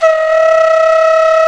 Index of /90_sSampleCDs/INIS - Opium/Partition H/DIZU FLUTE
DIZI02D#3.wav